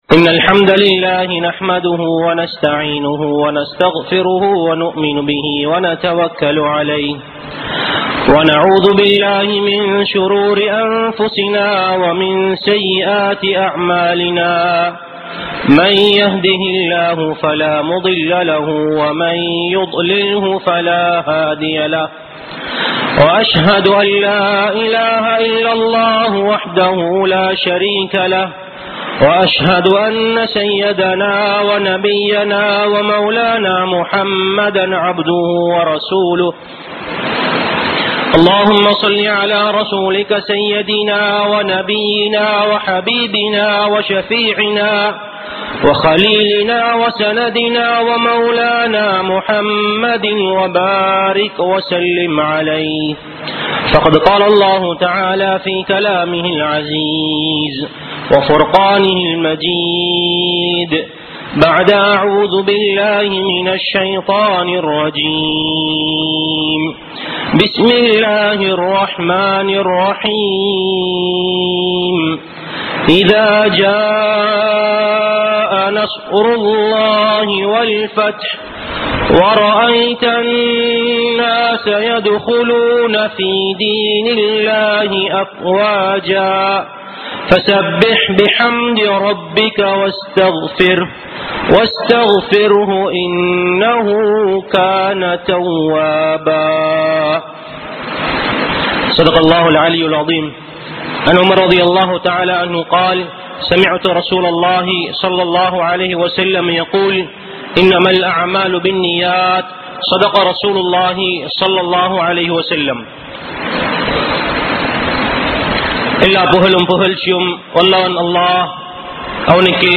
Paavangalai Vittu Vilahi Iruppoam (பாவங்களை விட்டு விலகியிருப்போம்) | Audio Bayans | All Ceylon Muslim Youth Community | Addalaichenai
Jumua Masjidh